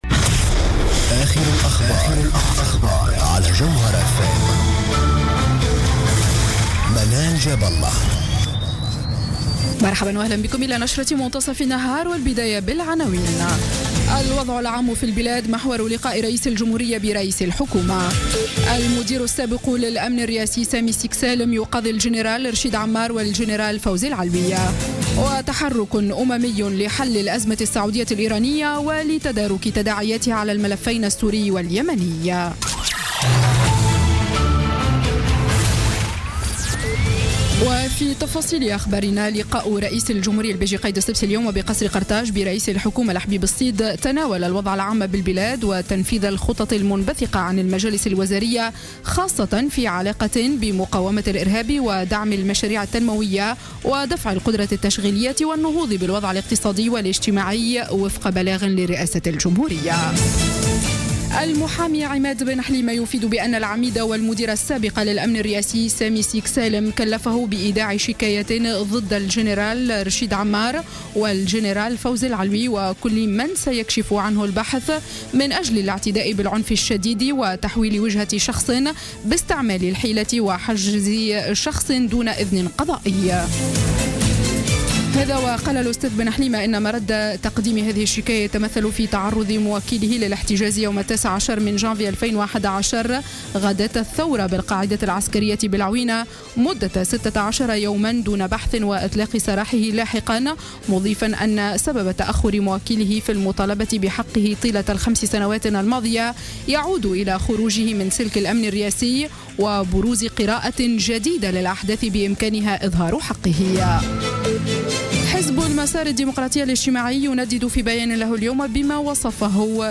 نشرة أخبار منتصف النهار ليوم الثلاثاء 5 جانفي 2016